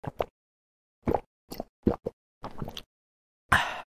دانلود آهنگ نوشیدن آب از افکت صوتی انسان و موجودات زنده
دانلود صدای نوشیدن آب از ساعد نیوز با لینک مستقیم و کیفیت بالا
جلوه های صوتی